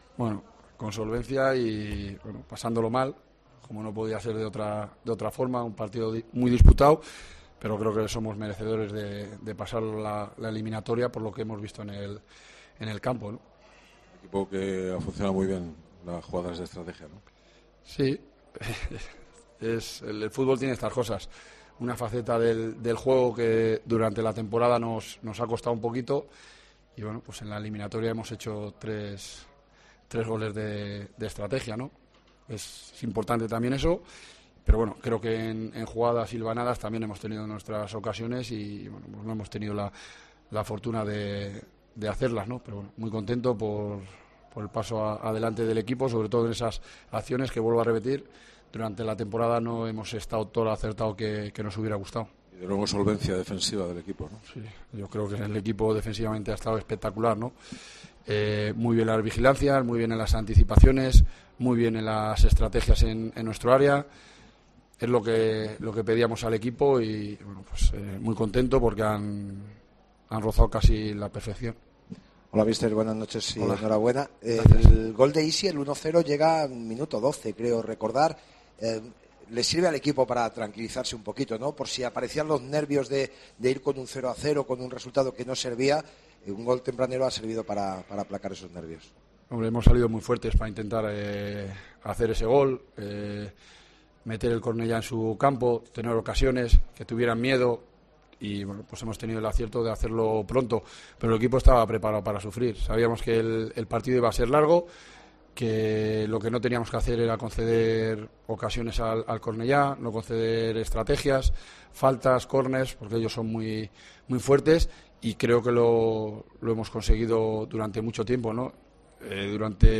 Escucha aquí las palabras del míster de la Deportiva, Jon Pérez Bolo, tras la victoria 2-0 ante el Cornellá